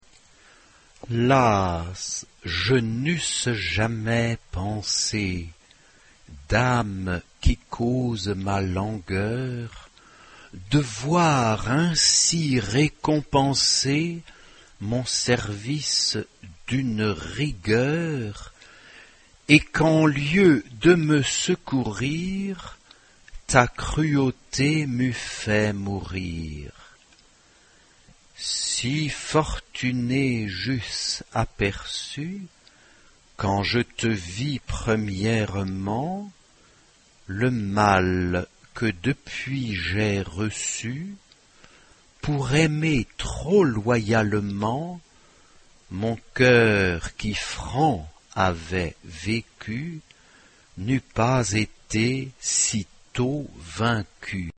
Zeitepoche: 16. Jh.  (1550-1599)
Genre-Stil-Form: weltlich ; Liedsatz
Charakter des Stückes: lebhaft
Chorgattung: SATB  (4 gemischter Chor Stimmen )
Tonart(en): g-moll